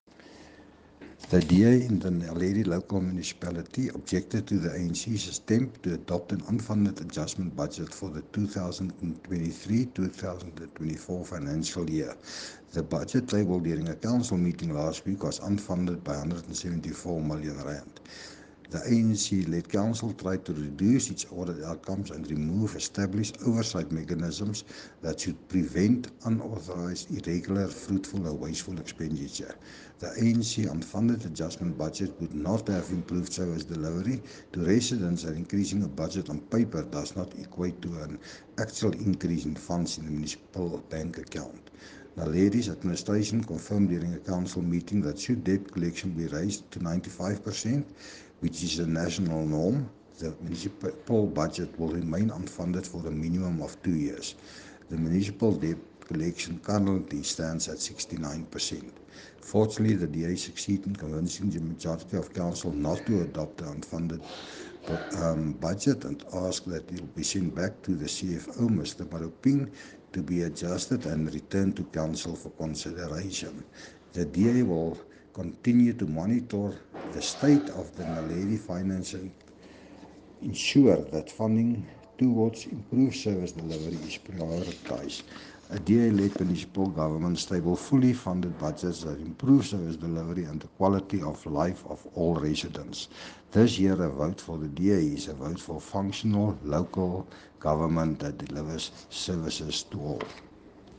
Note to Broadcasters: Please find linked soundbites in
English and Afrikaans by Cllr Jan Brand.